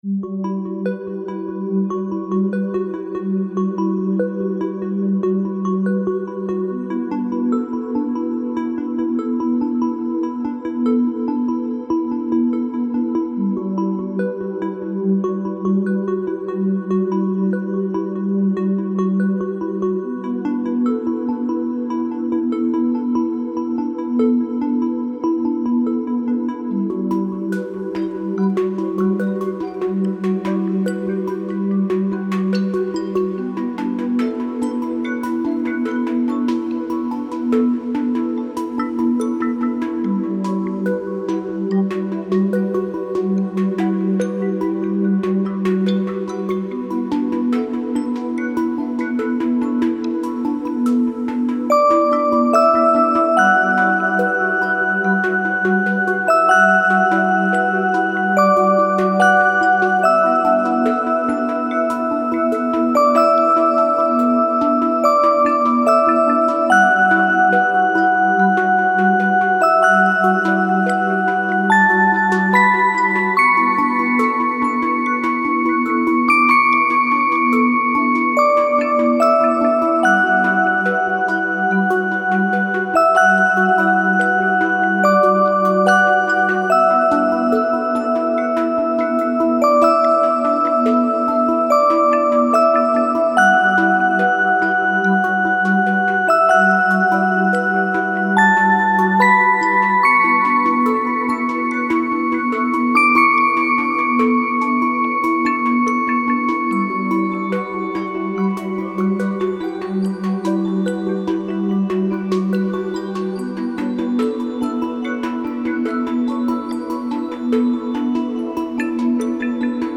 切なさ全開のシンセサイザーの曲です　シンプルがゆえに万能